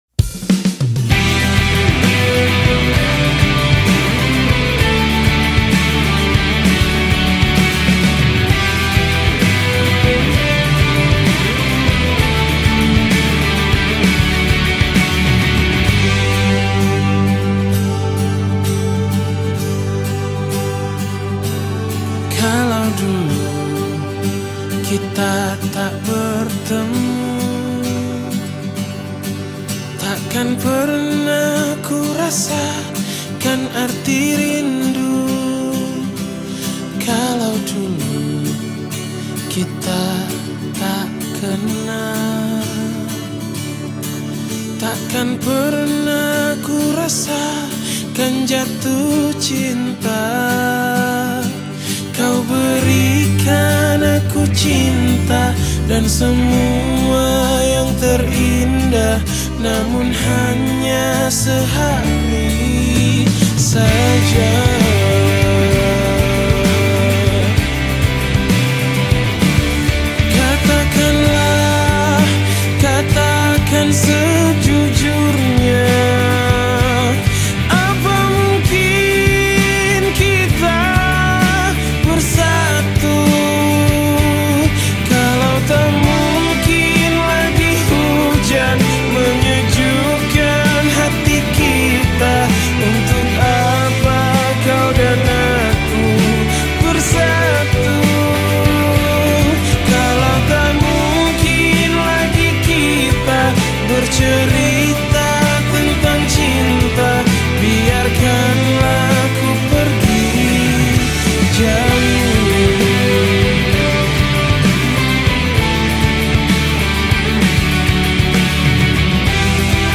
Genre Musik                      : Pop Melayu, Pop